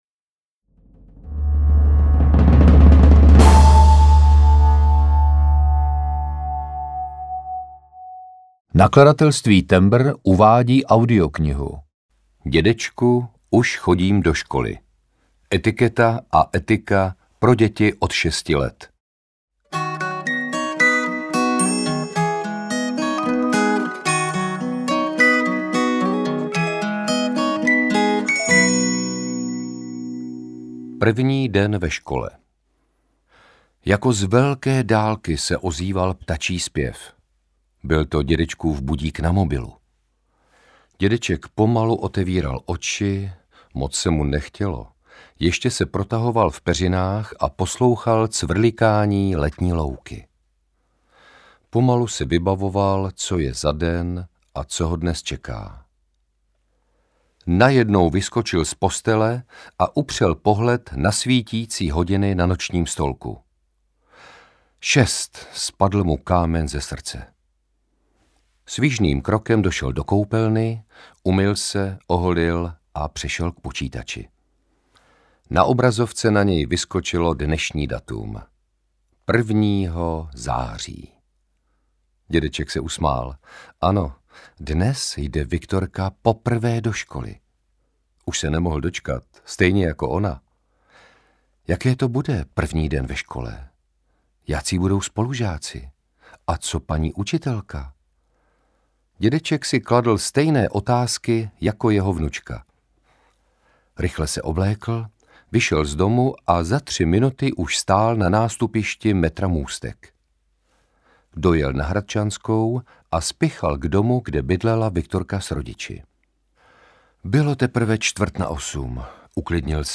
Ukázka z knihy
• InterpretLadislav Špaček